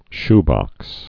(shbŏks)